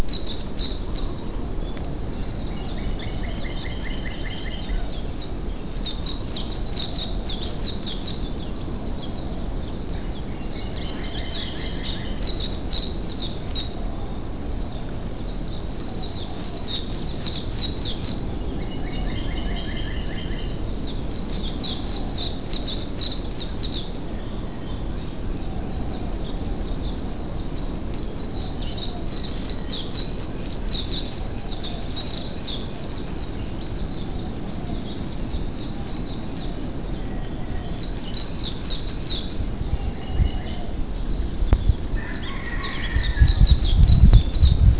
flowers in akaka falls park more smaller falls in akaka falls park the hugest fall in akaka falls park. ok, that's a lie, it's a teeny tiny stream going over some small rocks.